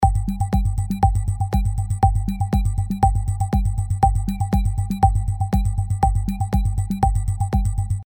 Home/Sound Effects/Breaking News Loop 1
Breaking News Loop 1